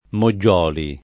[ mo JJ0 li ]